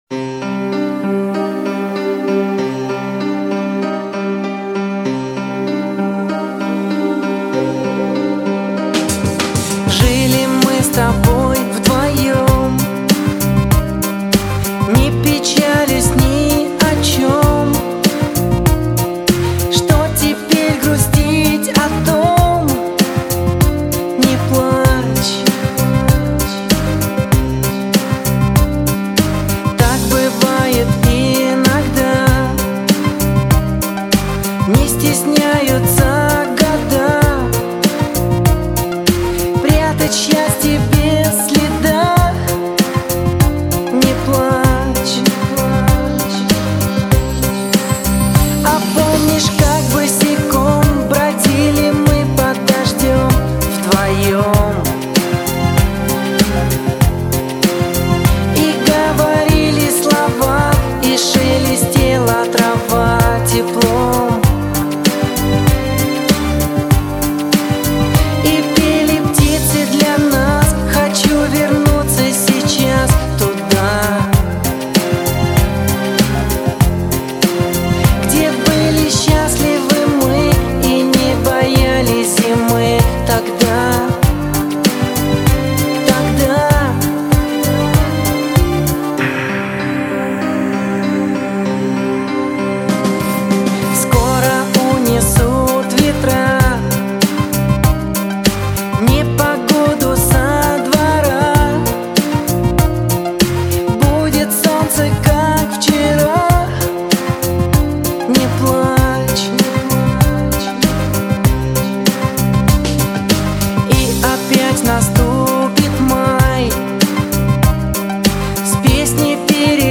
Классная медленная песенка, мне очень нравится...